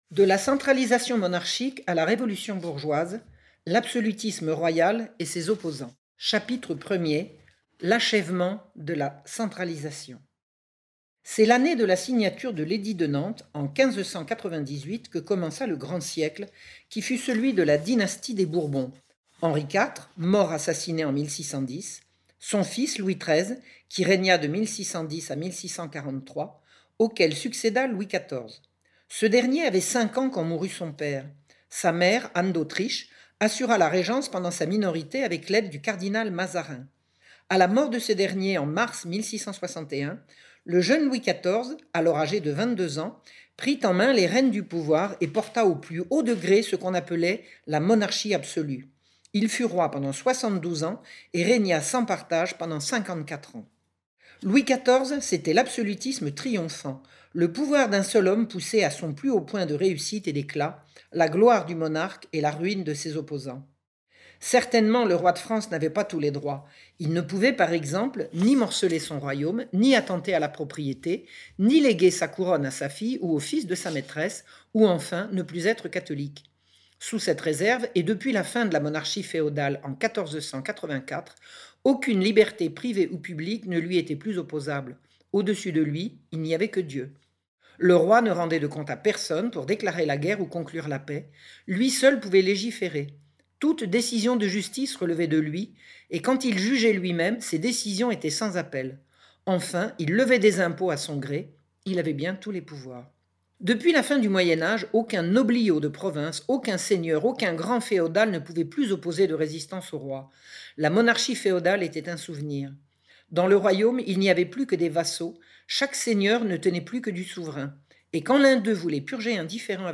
De la centralisation monarchique à la révolution bourgeoise - Livre audio